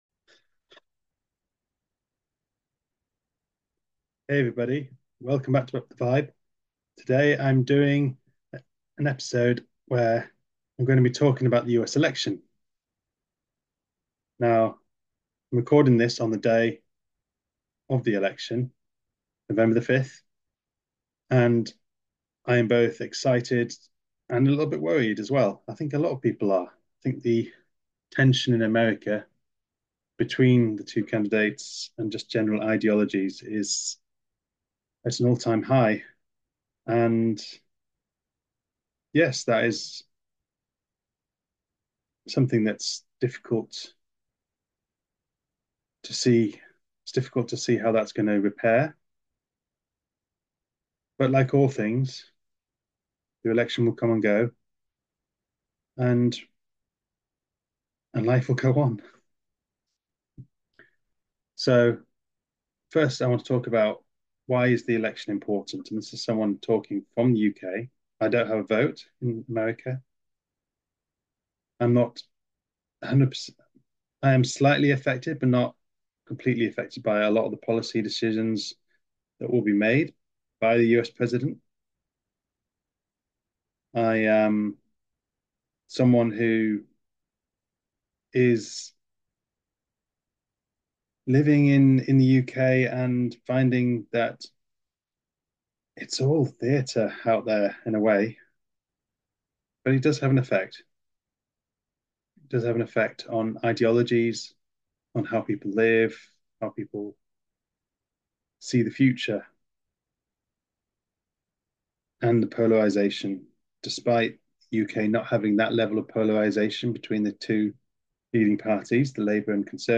In this episode I have done my first solo podcast on the eve of the 2024 US election to give my thoughts on the candidates, why the election is important and how both candidates might be part of the disclosure movement.